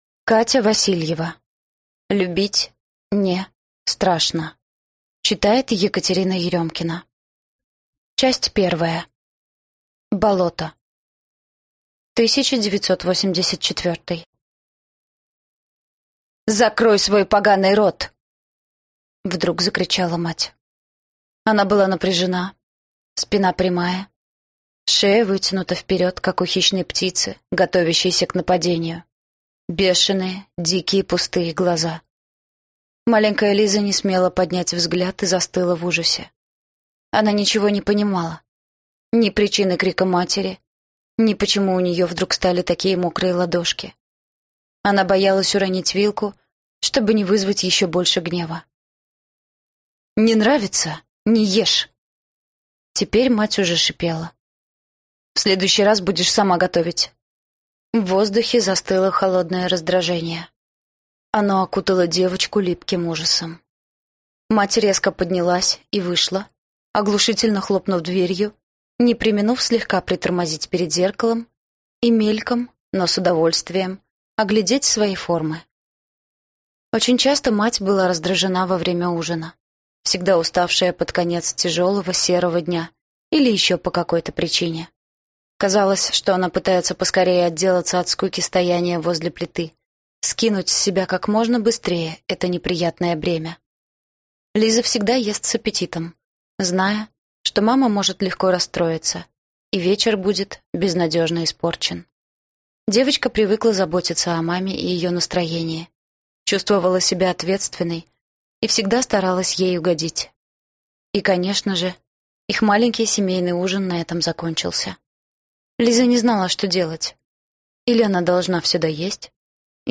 Аудиокнига Любить (НЕ) страшно | Библиотека аудиокниг